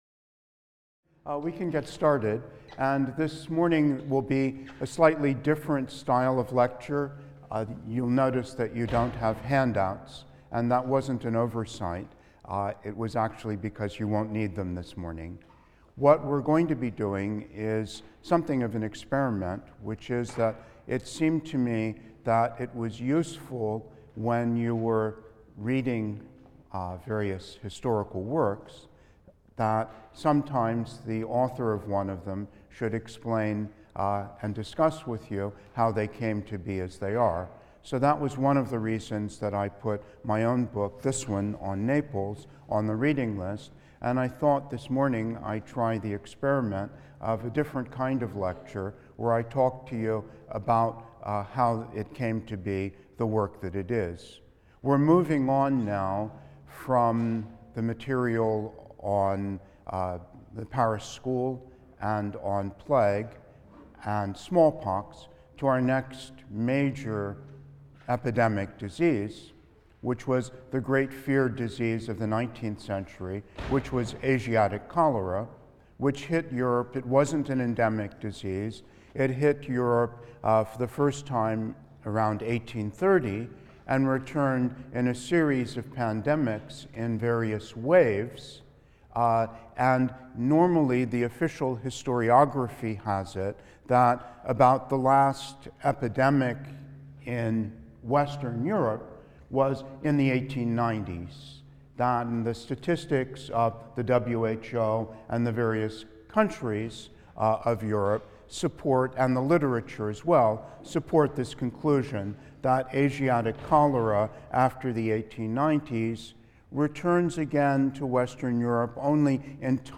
HIST 234 - Lecture 9 - Asiatic Cholera (I): Personal Reflections | Open Yale Courses